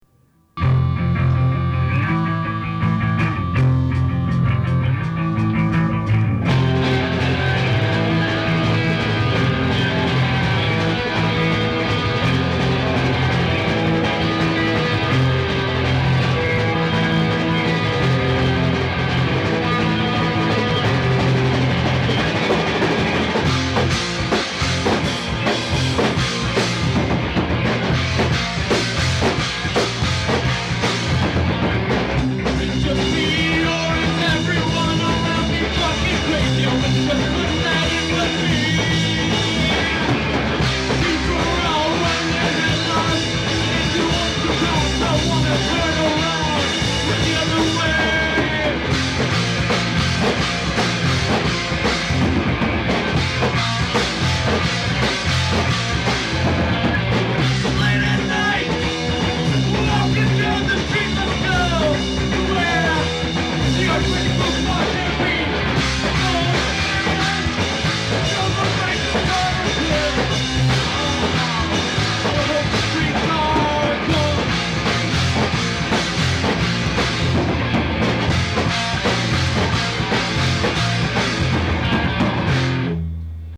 Recorded live on tour ’93
fancy Texas punk